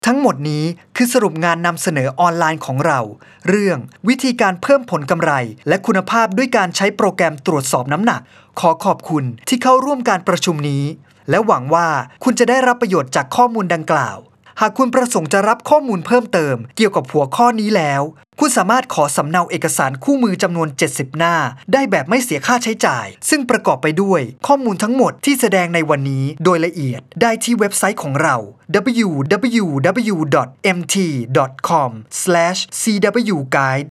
i have a home recording studio with considerable audio equipments such as Nueman TLM 103 microphone, Focusrite Class A preamp, KRK V6 studio monitor and Digidesign Mbox.
I am a professional Thai voice talent.
Sprechprobe: Industrie (Muttersprache):